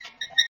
wheel3.mp3